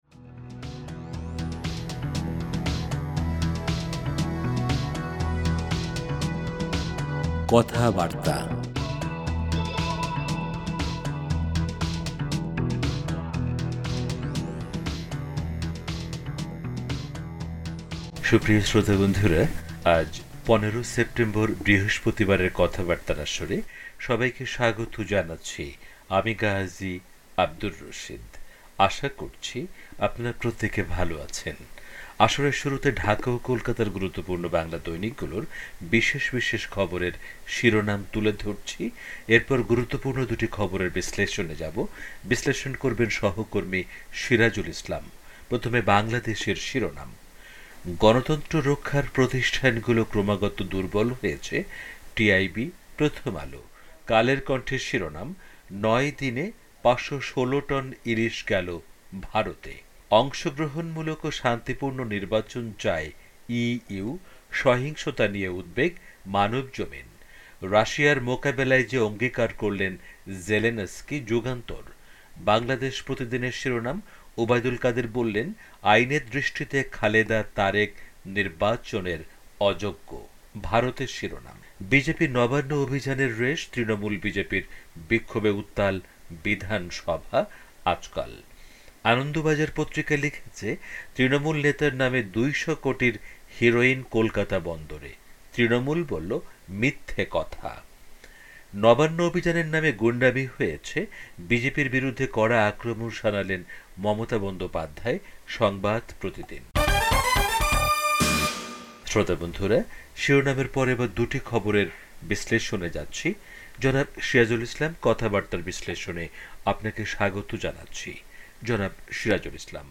রেডিও